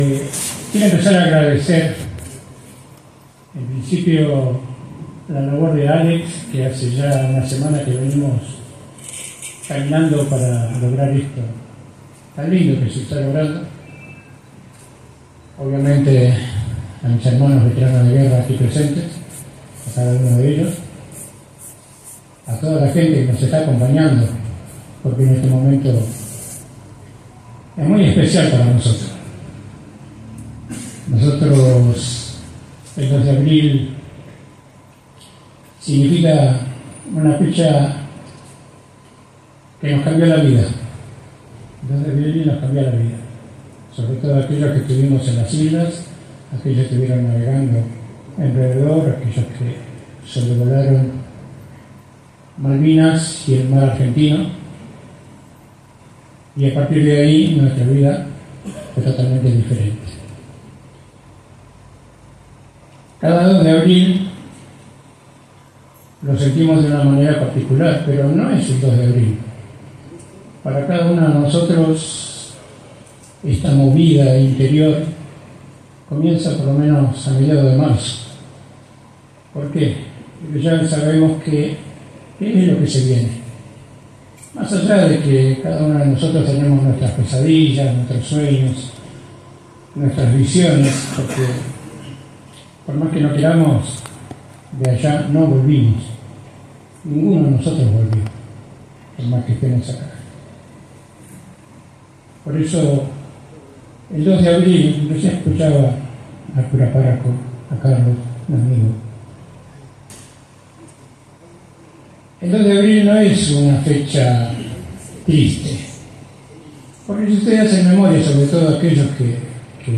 La vigilia, conocida como “Noche de Gloria”, se realizó el miércoles por la noche en el salón Cura Monguillot.